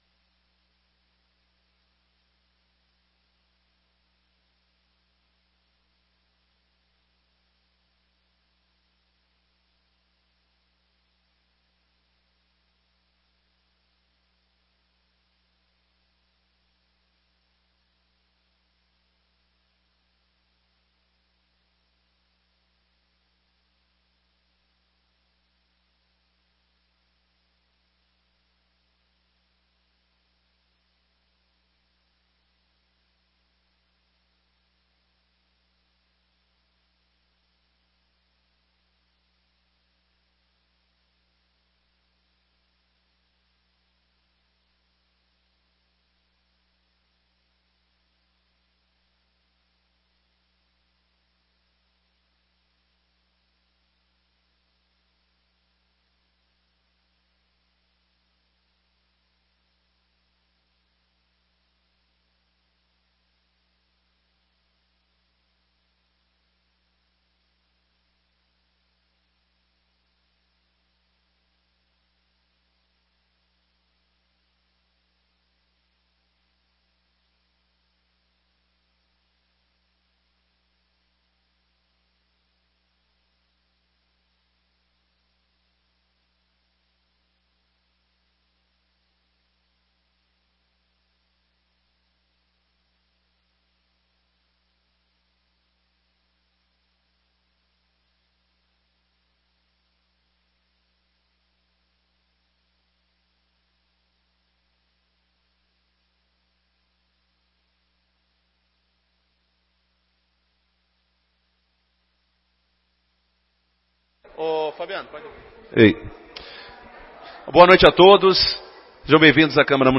08/11/2018 - Audiência Pública para discussão do projeto sobre a reorganização do estatuto e do plano de carreira do magistério público